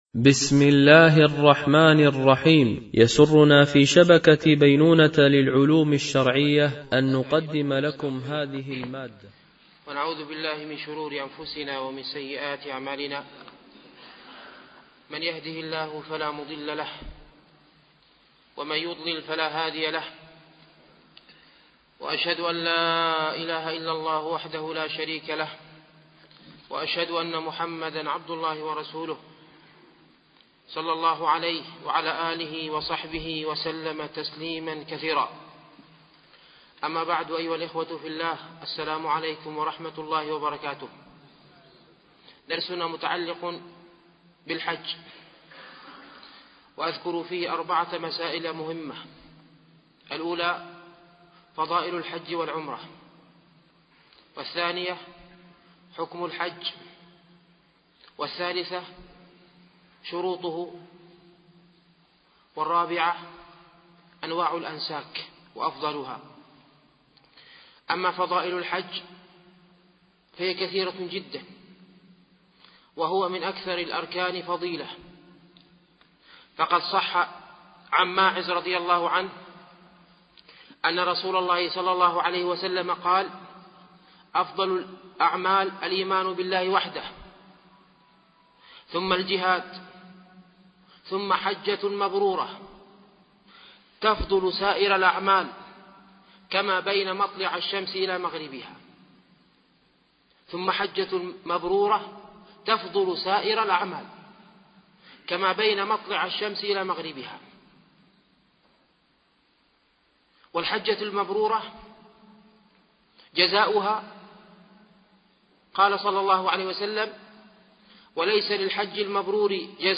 مناسك الحج ـ الدرس الأول